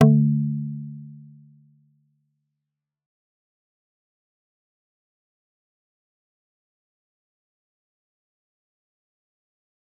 G_Kalimba-D3-f.wav